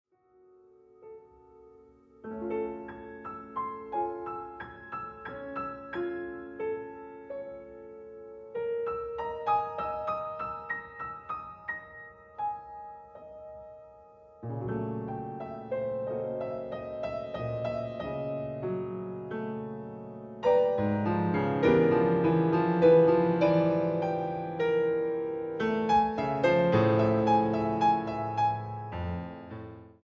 Preludios para piano 2a.